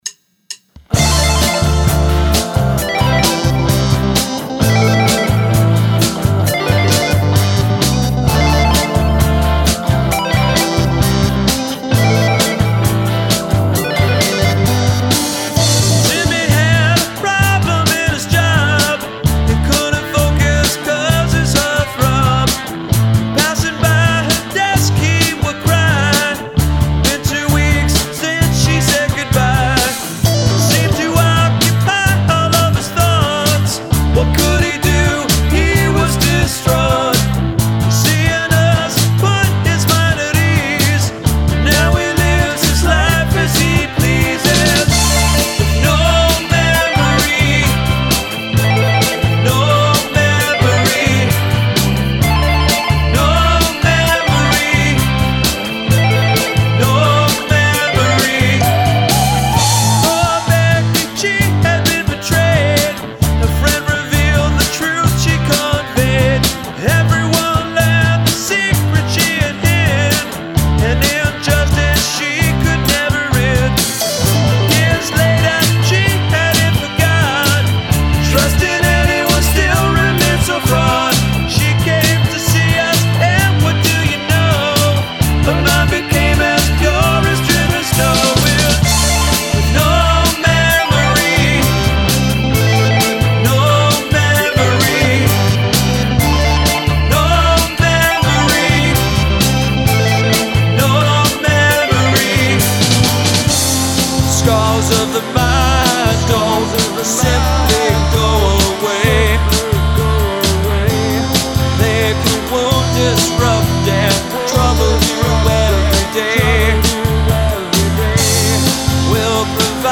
Elements of chiptune/ computer sounds